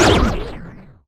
ruff_atk_dryfire_01.ogg